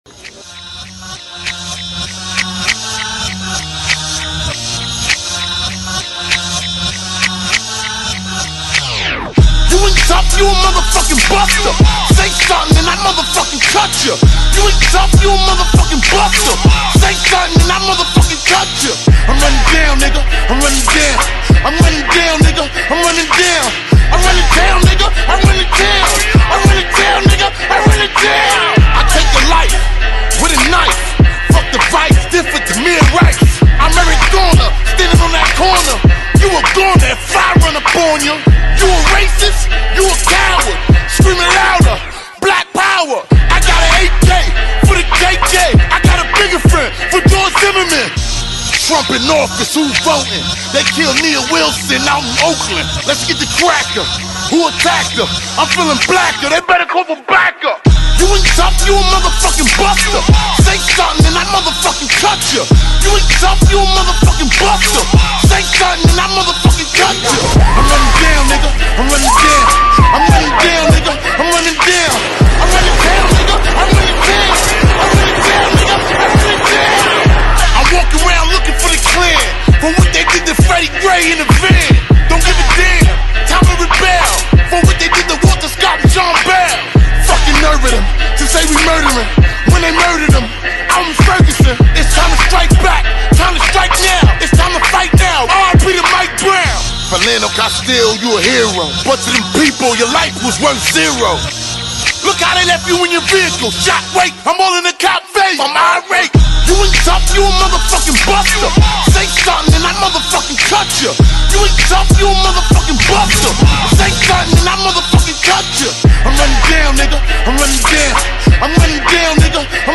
Veteran rapper
is a heavy hitting song with lots of electronic sound.
The song is loud and viby, not a typical tribute song.